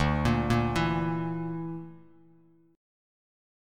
Dsus2#5 chord